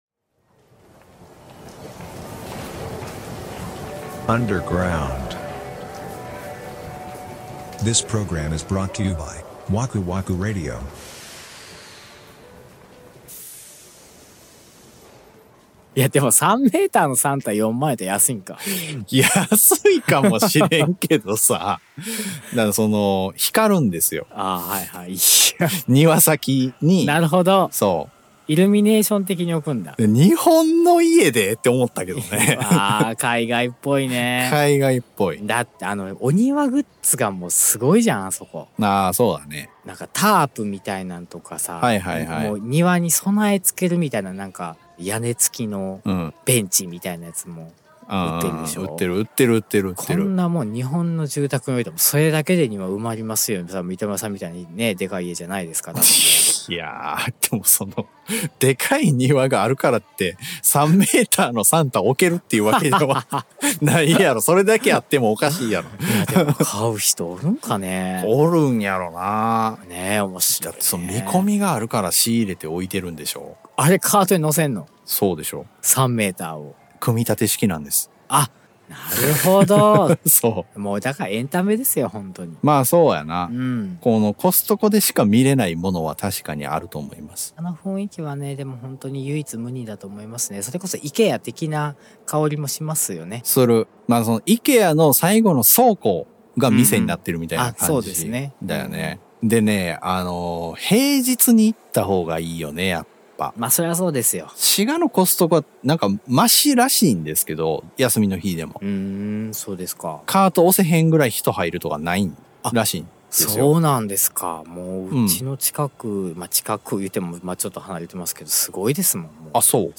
日常観察家事情報人生共感型 ジャスト10分バラエティ。 シュッとしたおっちゃんになりきれない、 宙ぶらり世代の２人が、 関西からお送りしています。